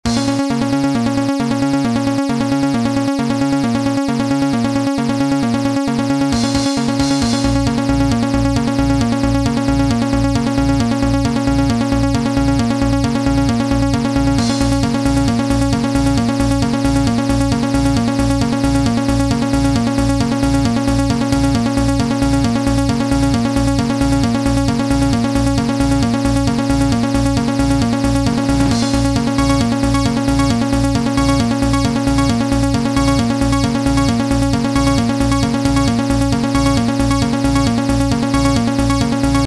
ダイナミックでハイエナジーなトランスのリズムにピコットの鋭い要素を融合させたかっこいい通知音や着信音です。